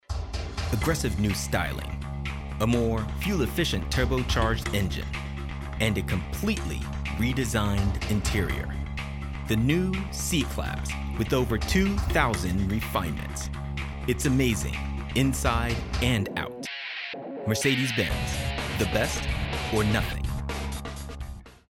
Male
English (North American)
Yng Adult (18-29), Adult (30-50)
Television Spots